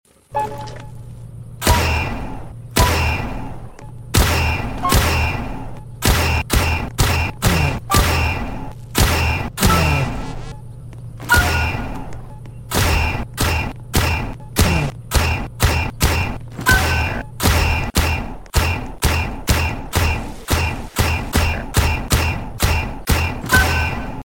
Laser Cannon Vs All Entities sound effects free download